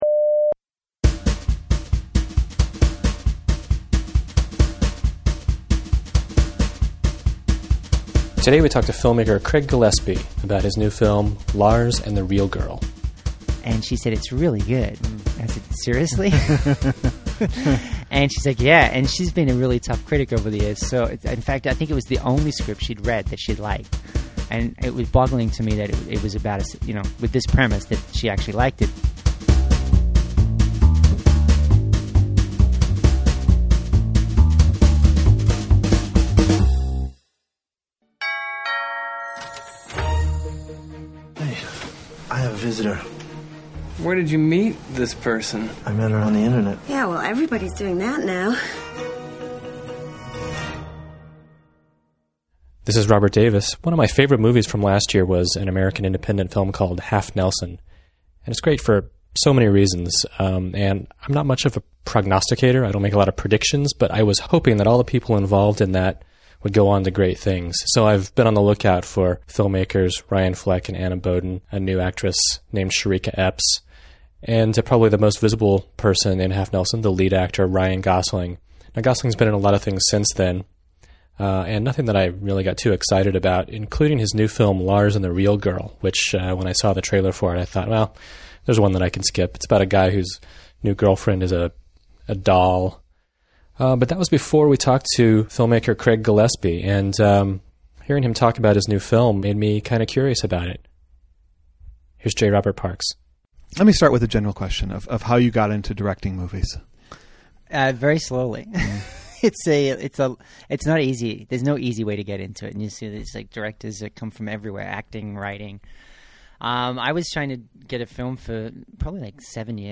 Errata: Interview: Lars and the Real Girl